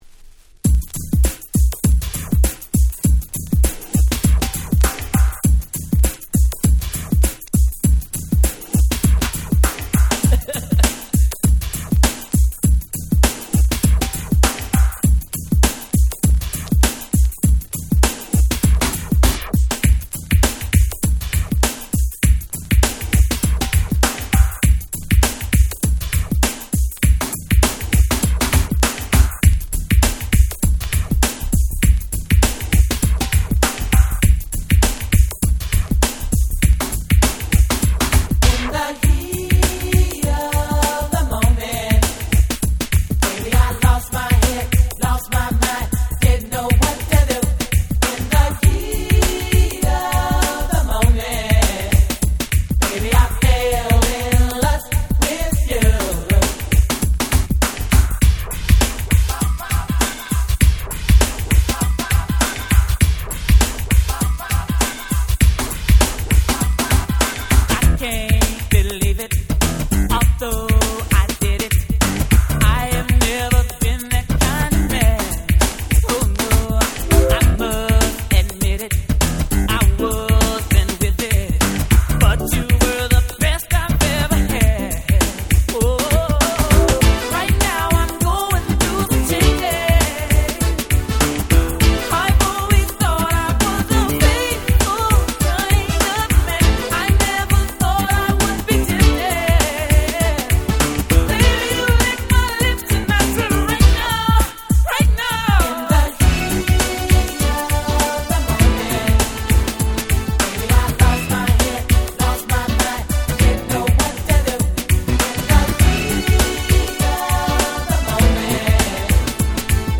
※一部試聴ファイルは別の盤から録音してございます。
90' Very Nice R&B / New Jack Swing !!
New Jack Swing Classics !!